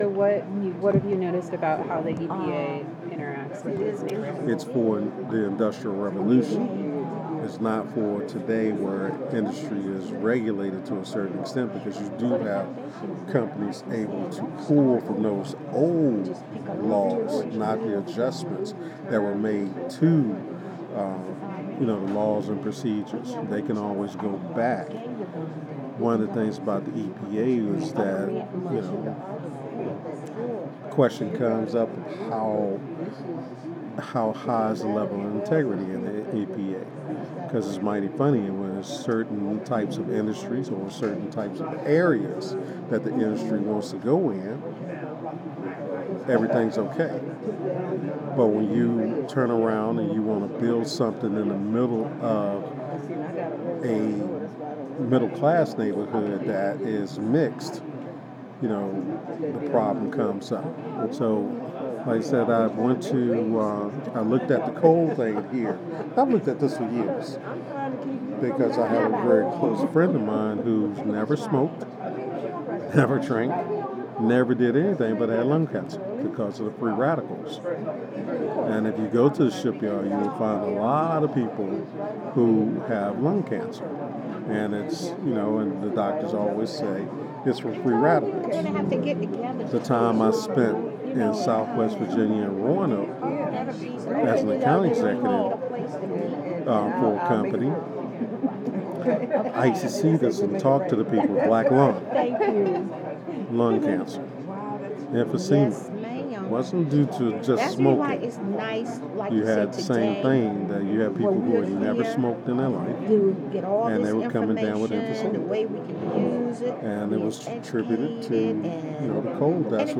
This is a short interview with an anonymous attendee of an event at Zion Baptist Church in East End Newport News called “The Air We Breathe,” co-sponsored by the Repair Lab, EmPowerAll and Zion Baptist Church. The interviewee describes the origins of his skepticism about the efficacy of the US Environmental Protection Agency (EPA).
Note: Because this recording was made informally during a break in a public event, there are several instances of background voices interrupting the interview. This file has been lightly edited to minimize interruptions.